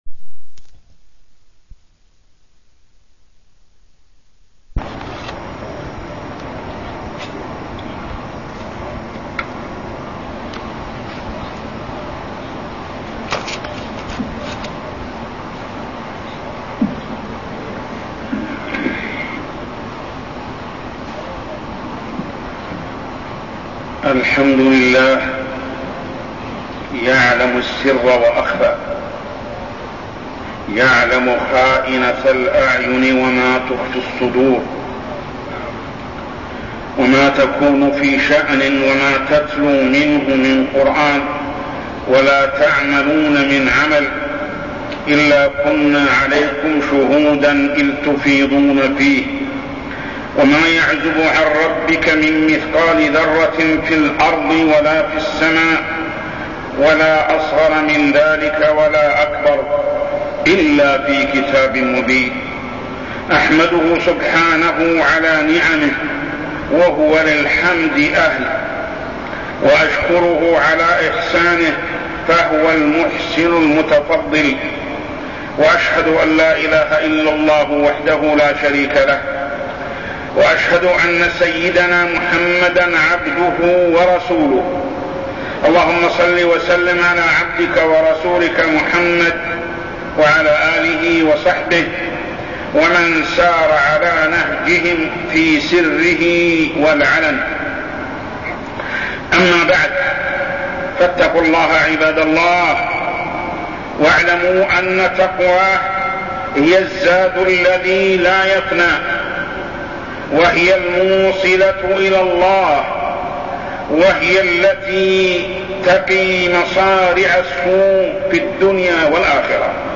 تاريخ النشر ٢٥ رجب ١٤١٤ هـ المكان: المسجد الحرام الشيخ: محمد بن عبد الله السبيل محمد بن عبد الله السبيل الإخلاص في العمل